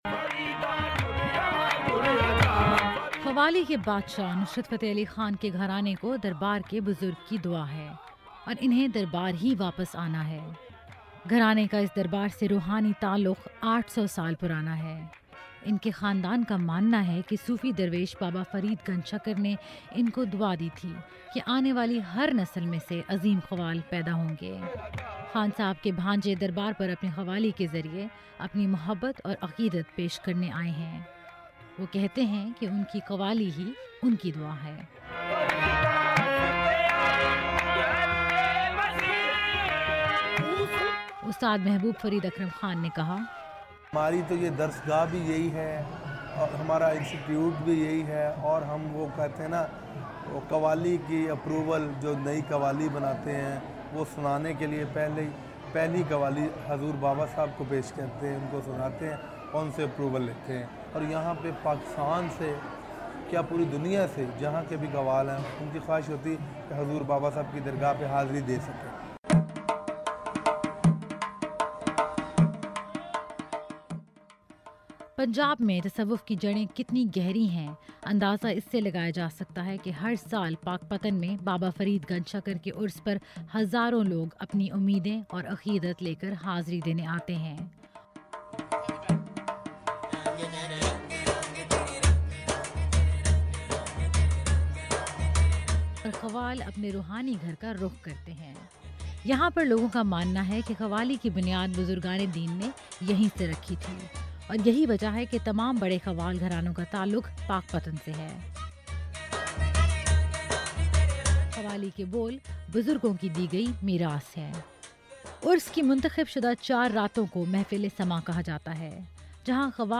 خصوصی رپورٹ